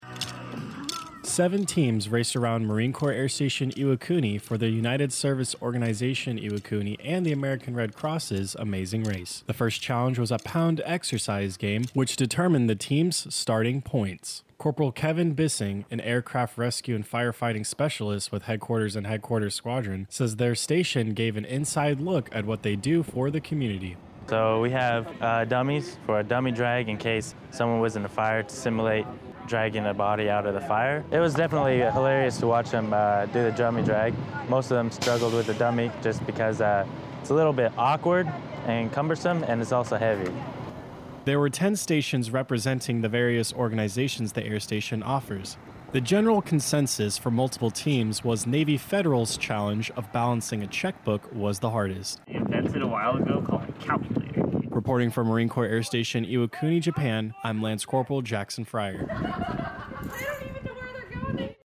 Iwakuni radio news story of “MCAS Iwakuni residents compete in Amazing Race (Package/Pkg)”.